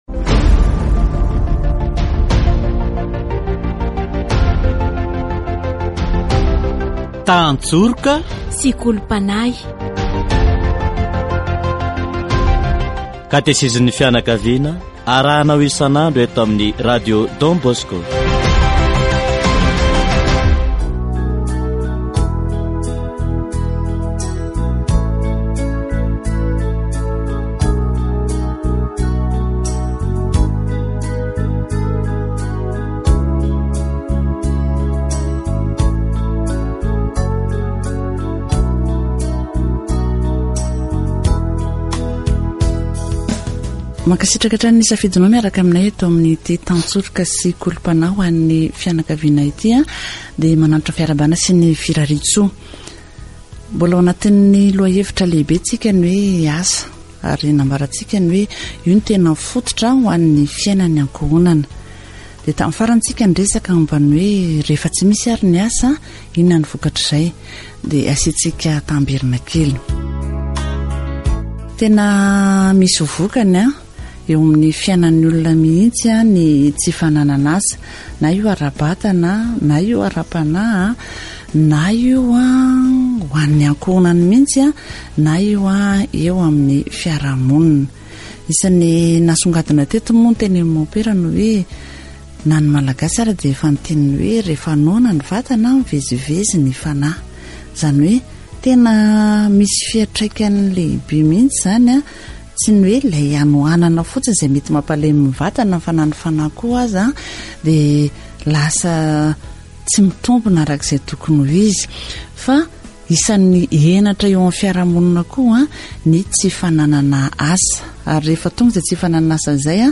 Catéchèse sur le travail